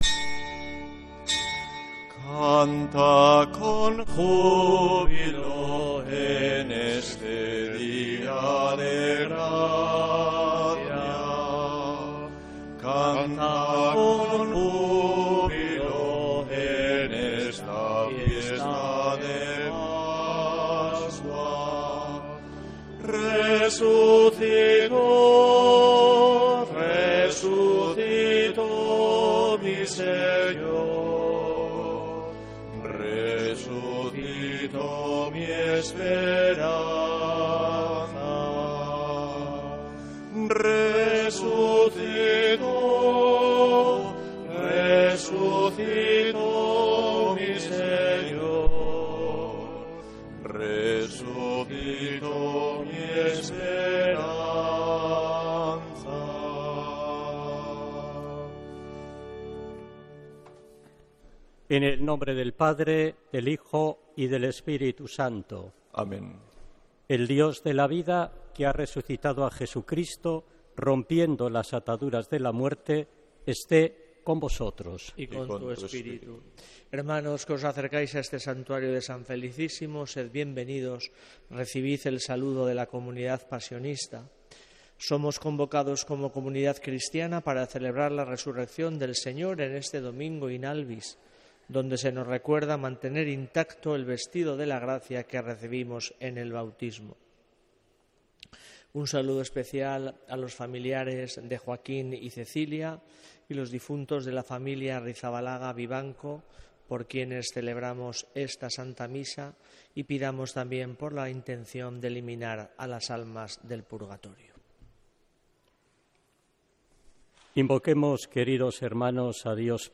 Santa Misa desde San Felicísimo en Deusto, domingo 12 de abril de 2026